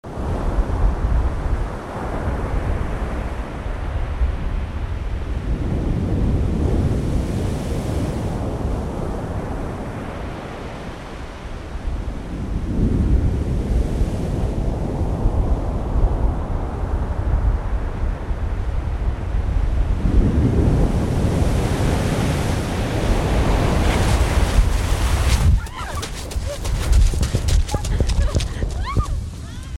East Coast Pebble Engulf
Tags: Travel Taiwan Taipei Sounds of Taiwan Vacation